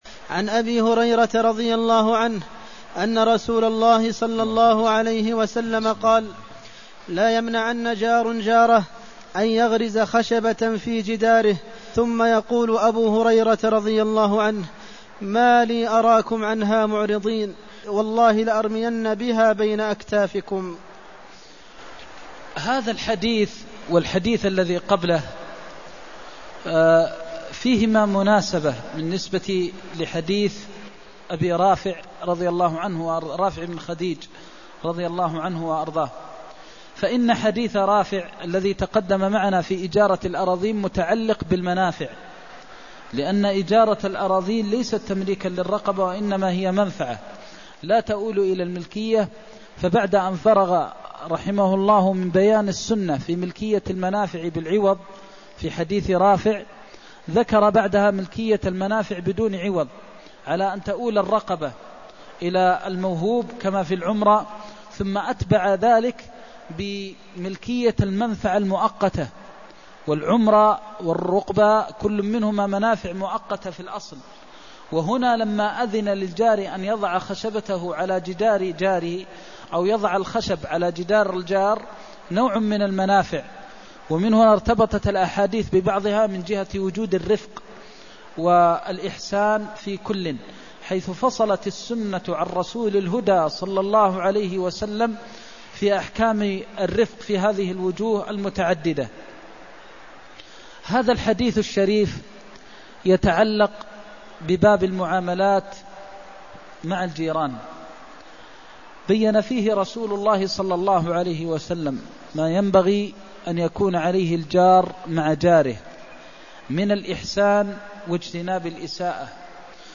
المكان: المسجد النبوي الشيخ: فضيلة الشيخ د. محمد بن محمد المختار فضيلة الشيخ د. محمد بن محمد المختار جواز غرز الخشب في جدار الجار (275)